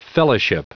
Prononciation du mot fellowship en anglais (fichier audio)